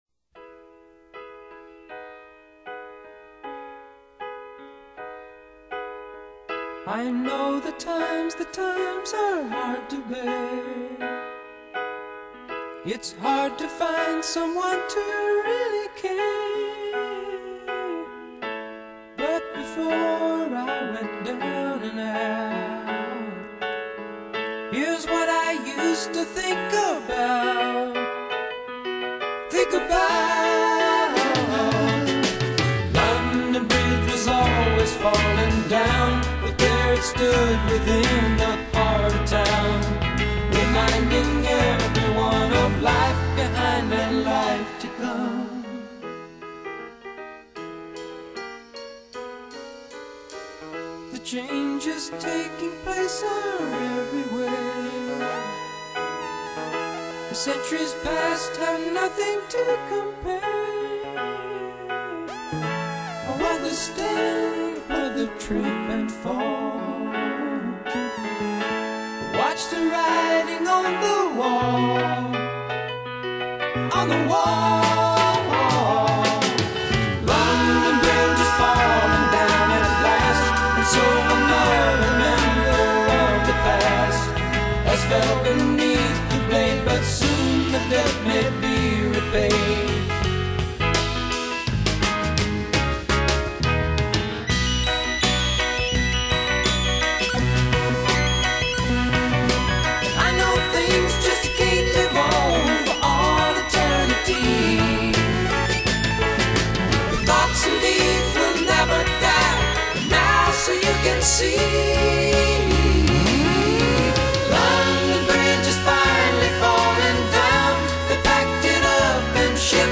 整張專輯活潑而多變